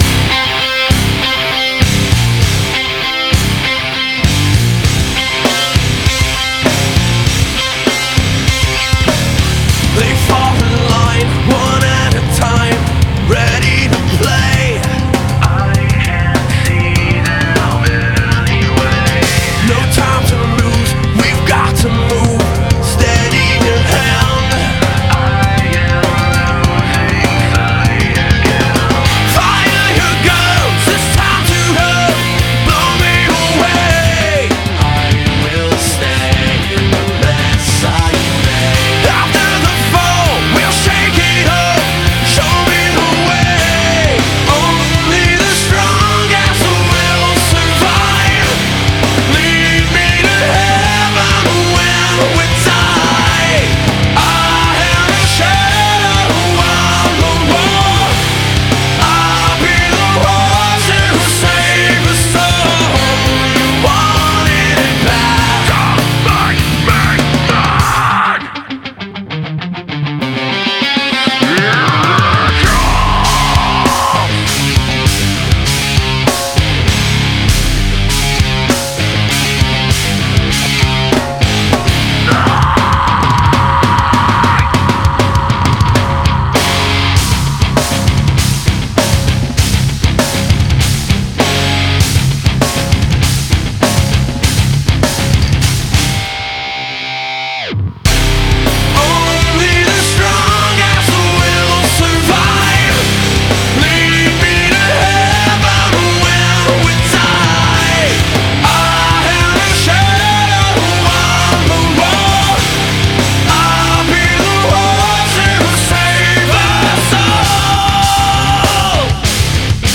BPM99--1
Audio QualityMusic Cut